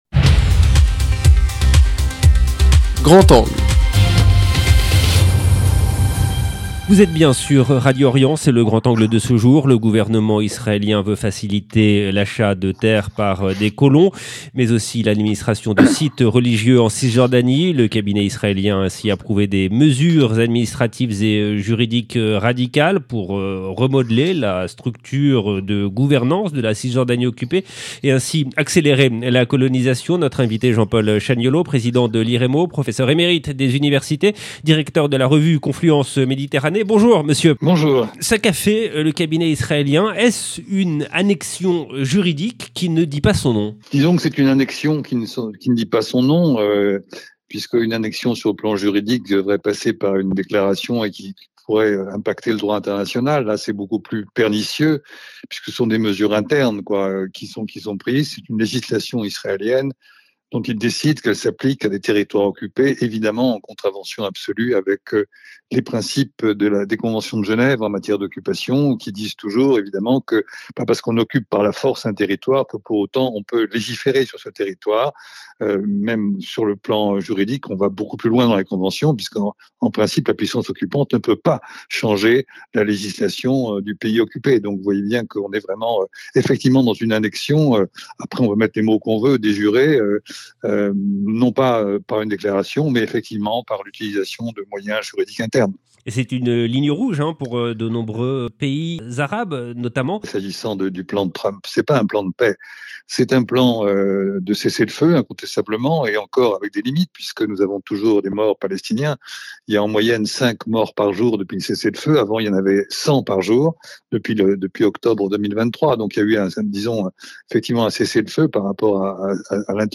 Le cabinet israélien a ainsi approuvé des mesures administratives et juridiques radicales visant à remodeler la structure de gouvernance de la Cisjordanie occupée et ainsi à accélérer la colonisation. Notre invité